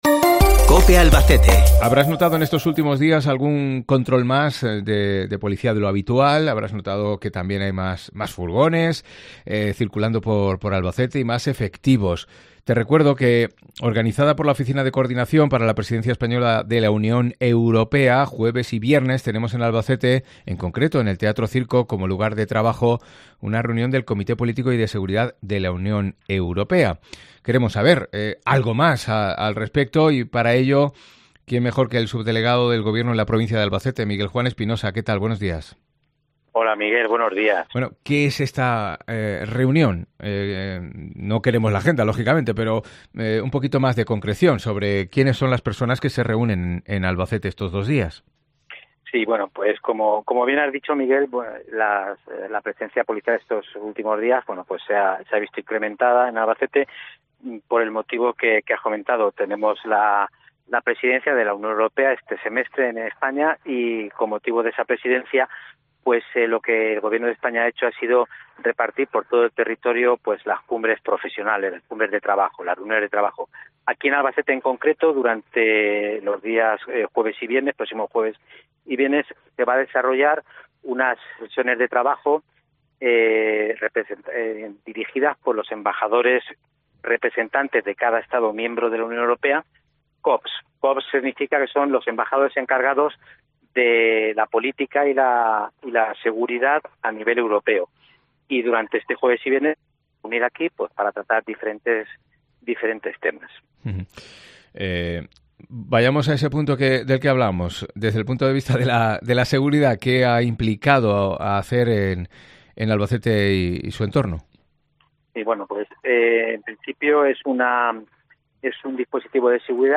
Miguel Juan Espinosa, subdelegado del Gobierno en la provincia de Albacete nos explica en qué consiste este encuentro